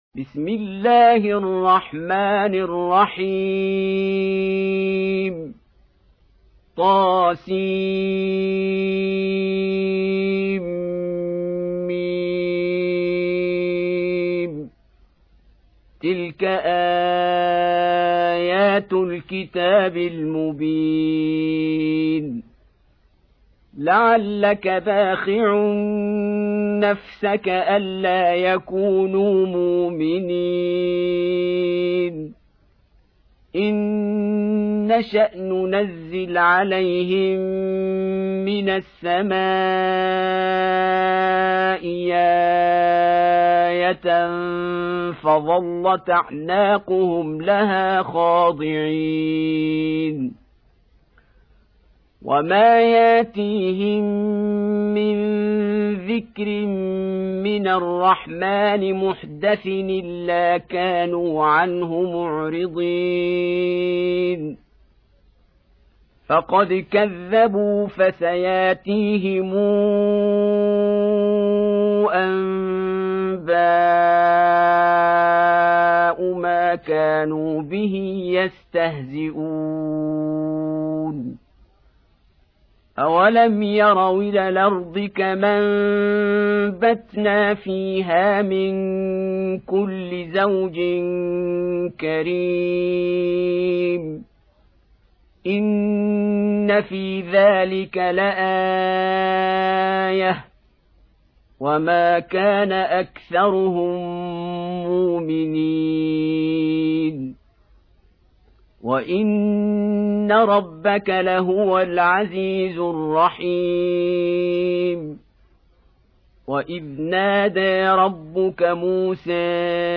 Surah Repeating تكرار السورة Download Surah حمّل السورة Reciting Murattalah Audio for 26. Surah Ash-Shu'ar�' سورة الشعراء N.B *Surah Includes Al-Basmalah Reciters Sequents تتابع التلاوات Reciters Repeats تكرار التلاوات